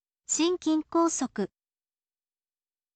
shinkin kousoku